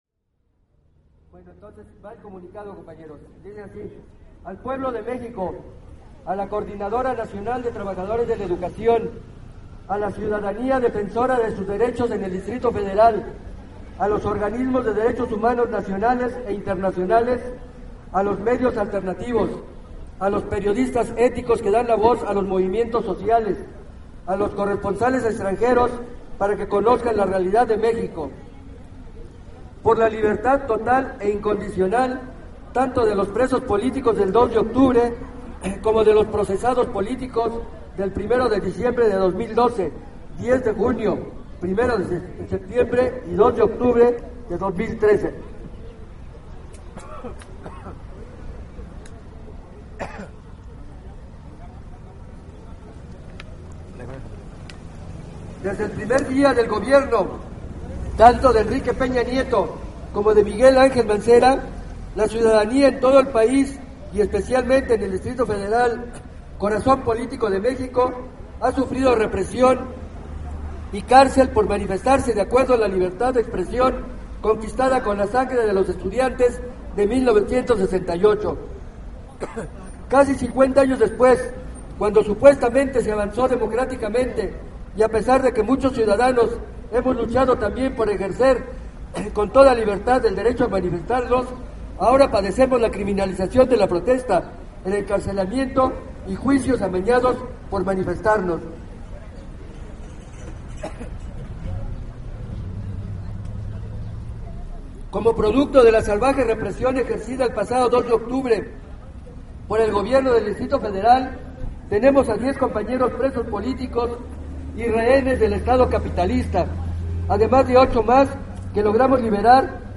Pasado del medio día, inició el mitin que daría paso a la instalación de las carpas para iniciar la huelga de hambre.
Comunicado central